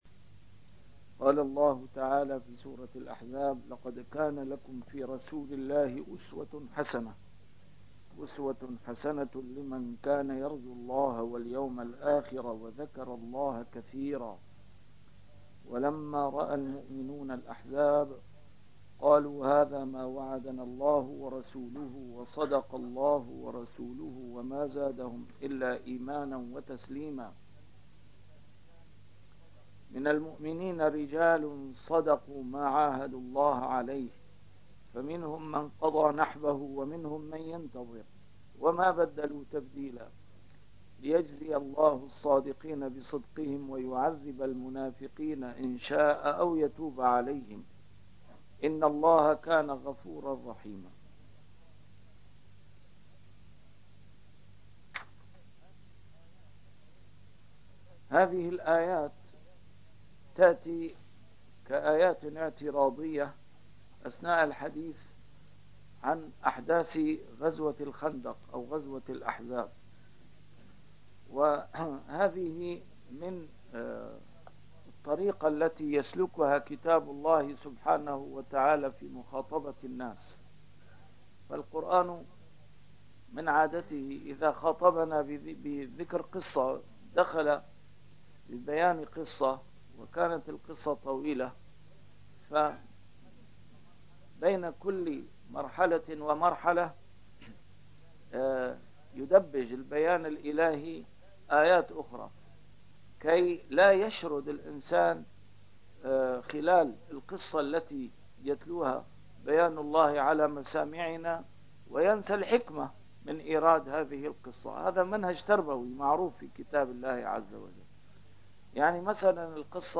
A MARTYR SCHOLAR: IMAM MUHAMMAD SAEED RAMADAN AL-BOUTI - الدروس العلمية - تفسير القرآن الكريم - تسجيل قديم - الدرس 369: الأحزاب 21-24